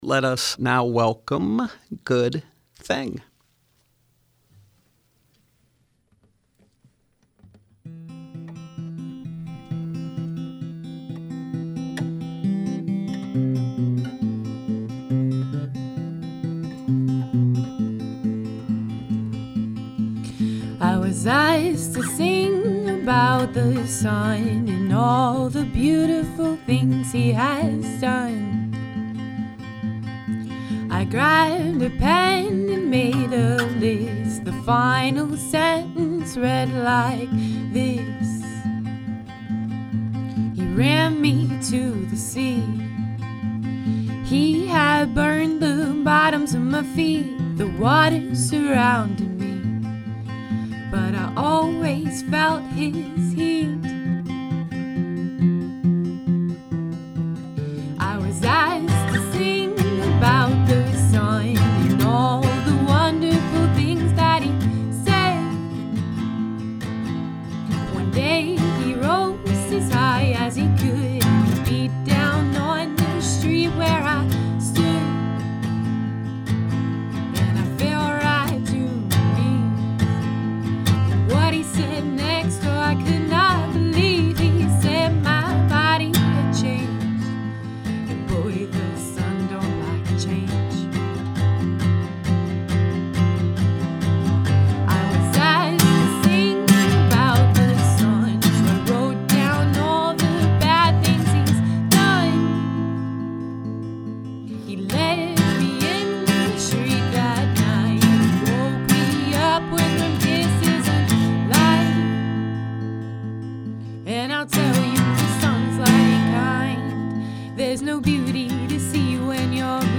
folk duo